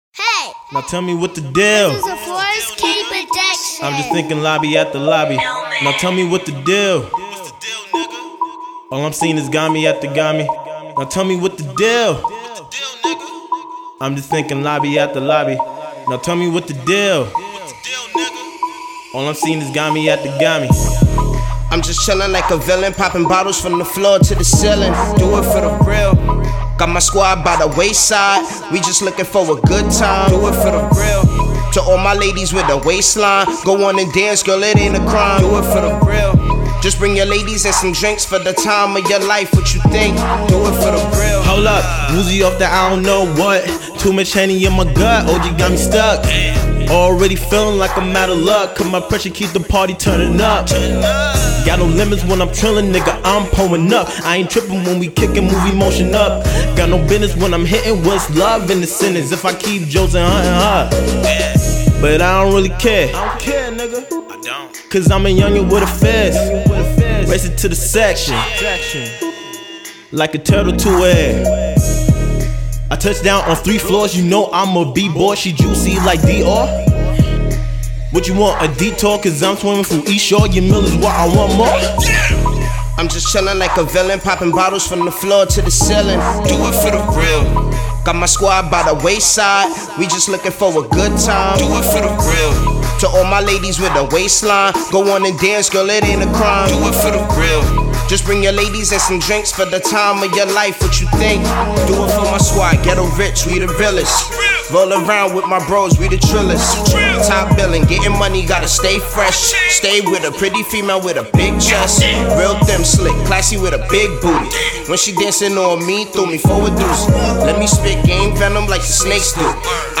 Hiphop
Club Banger
Displays A Chill Club Bounce